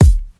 • Hip-Hop Bass Drum One Shot D# Key 191.wav
Royality free kick sound tuned to the D# note. Loudest frequency: 406Hz
hip-hop-bass-drum-one-shot-d-sharp-key-191-A1A.wav